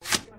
menu_buy.mp3